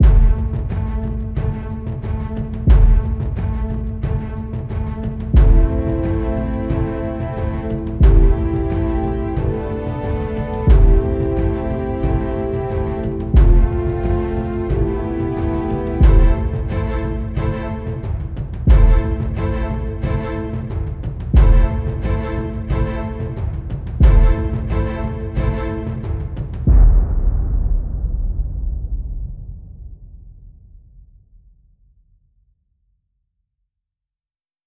Category: Orchestral